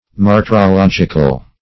Search Result for " martyrological" : The Collaborative International Dictionary of English v.0.48: Martyrologic \Mar`tyr*o*log"ic\, Martyrological \Mar`tyr*o*log"ic*al\, a. Pertaining to martyrology or martyrs; registering, or registered in, a catalogue of martyrs.